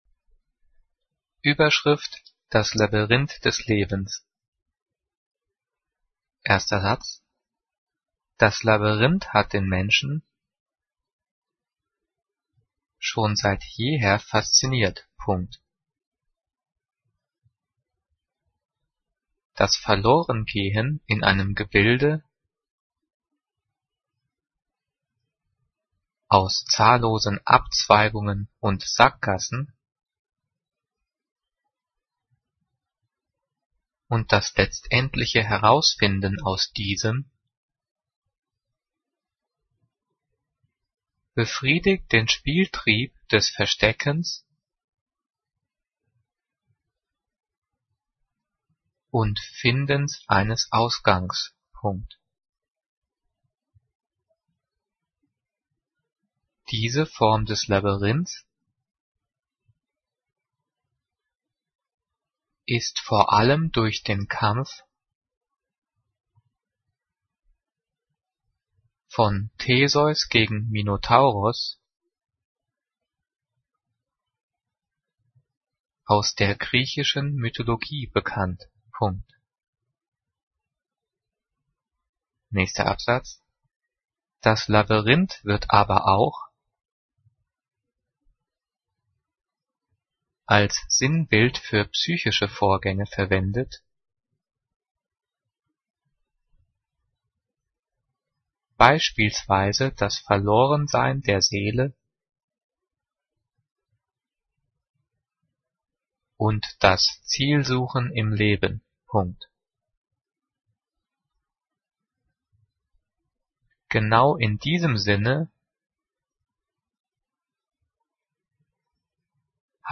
Diktat: "Das Labyrinth des Lebens" - 9./10. Klasse - Getrennt- und Zus.
Diktiert: